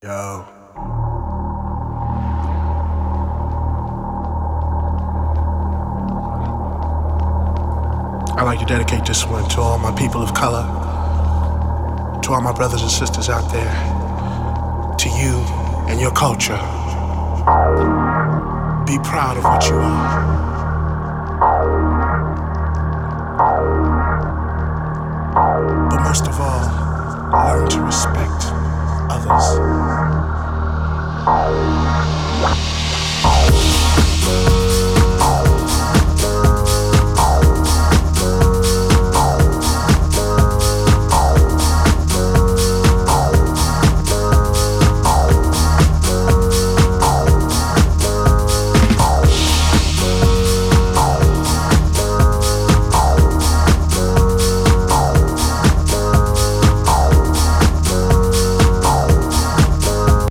audible distortion